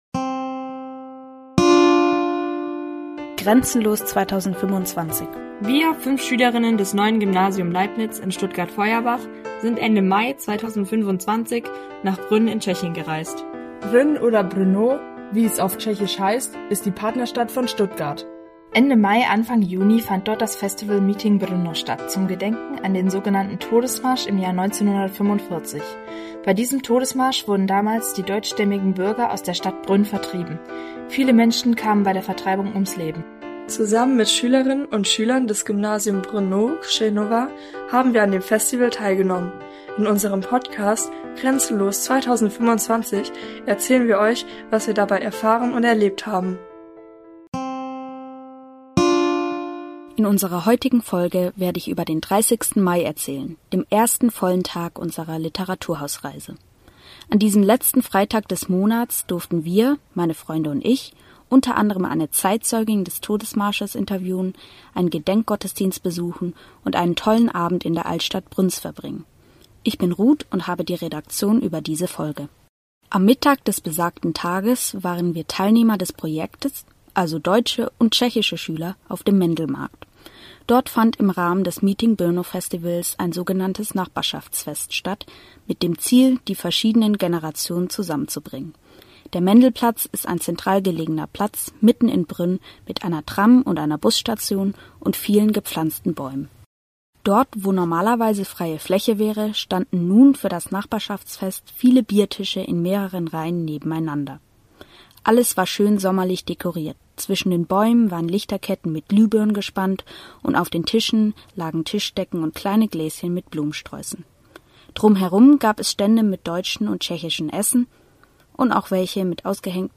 Das Festival Meeting Brno